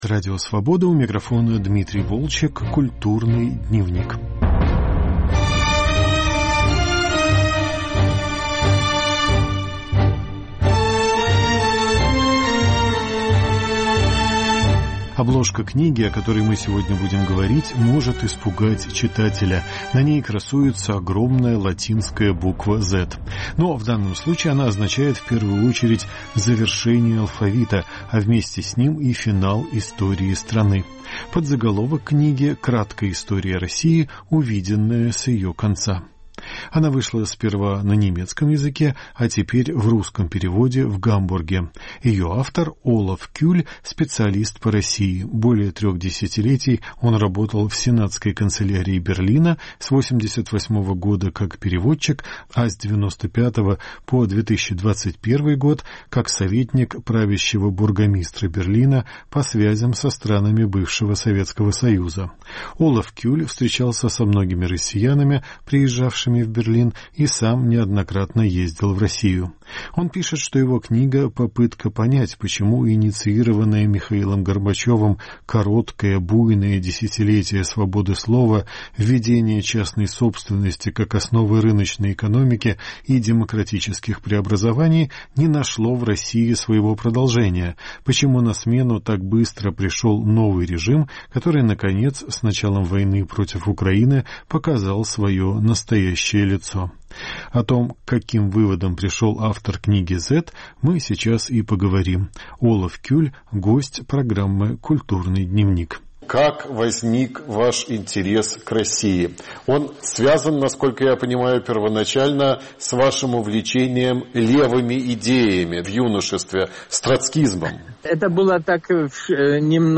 Разговор о книге “Z”.